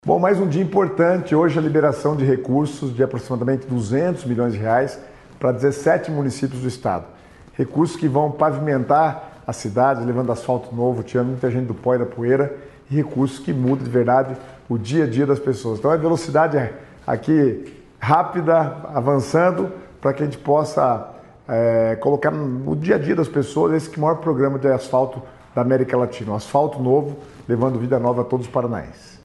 Sonora do secretário das Cidades, Guto Silva, sobre a autorização de R$ 198 milhões em licitações para obras em 17 municípios do Paraná